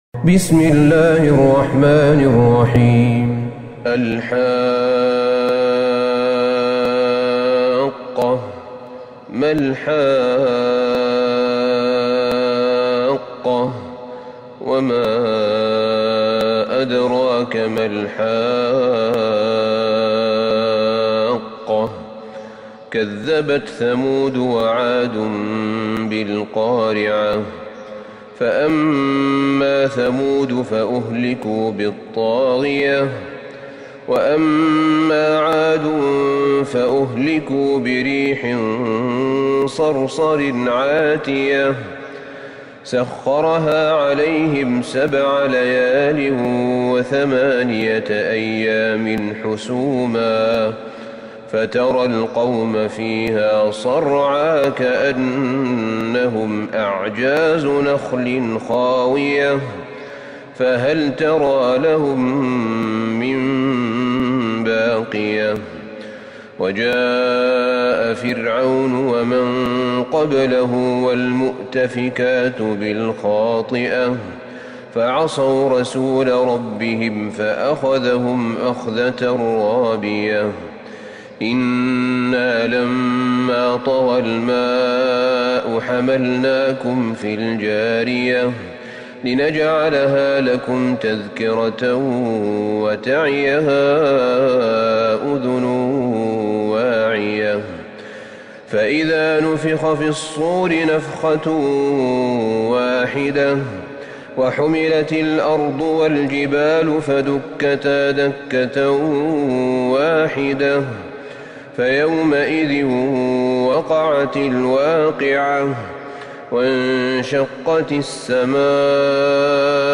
سورة الحاقة Surat Al-Haqqah > مصحف الشيخ أحمد بن طالب بن حميد من الحرم النبوي > المصحف - تلاوات الحرمين